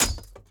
Sword Blocked 2.ogg